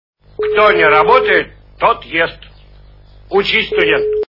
При прослушивании Операция Ы и другие приключения Шурика - Кто не работает тот ест Учись студент качество понижено и присутствуют гудки.